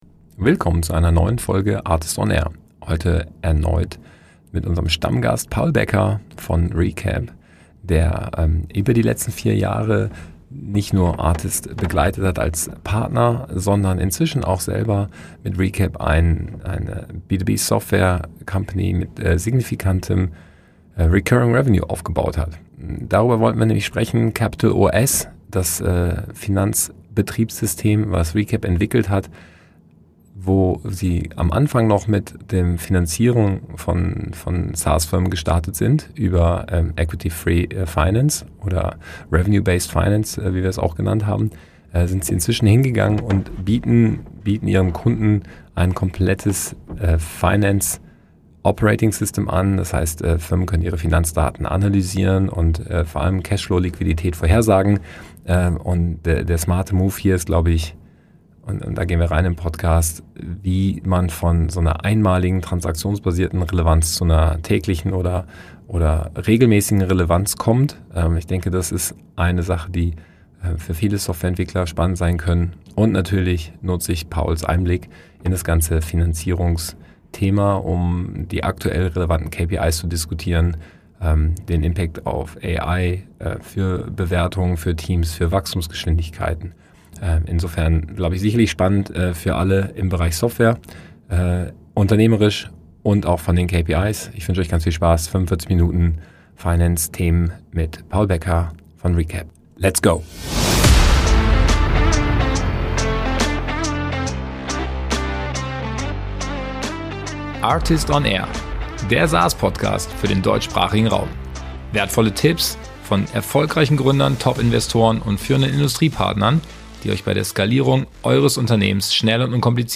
We talk about building software companies in Europe. In the ARRtist on AIR podcast, successful B2B SaaS founders, top investors, leading industry partners and experts provide their hard learnings, valuable tips and insights beyond the obvious. In the interviews, we talk to them about how software founders can increase ARR, how to scale an organisation, and which go-to-market strategies lead to success in the DACH market.